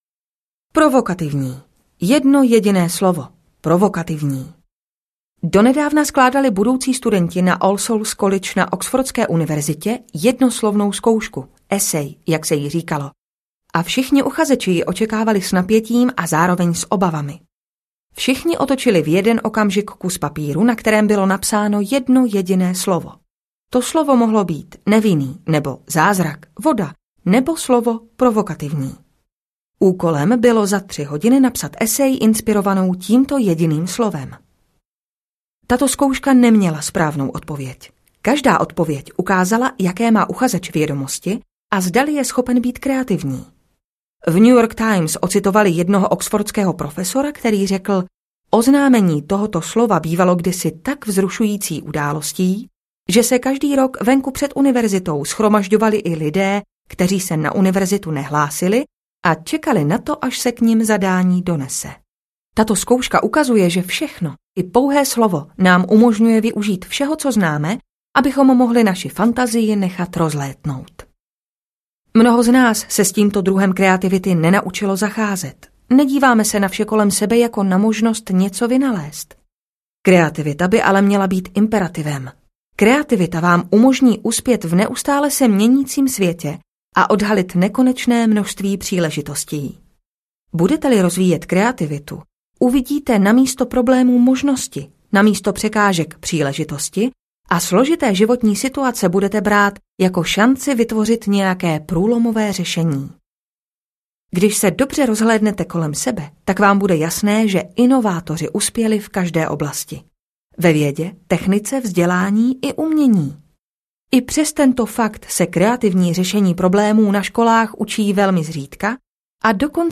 Génius v nás audiokniha
Ukázka z knihy
genius-v-nas-audiokniha